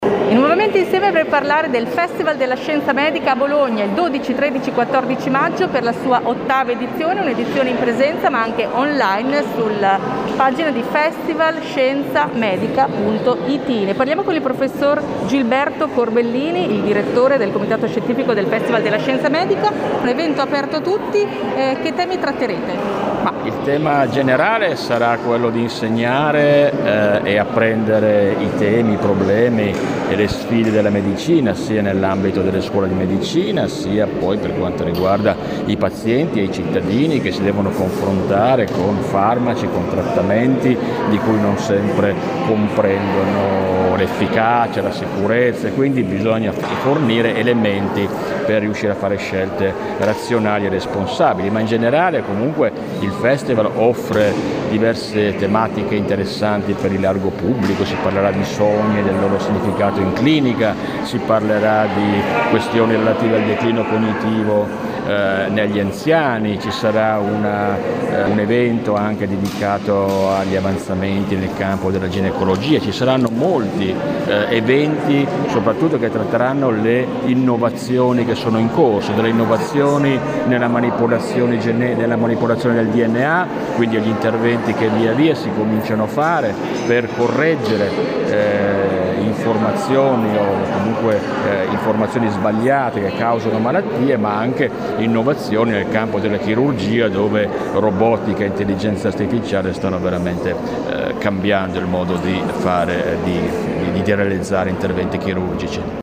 l’intervista a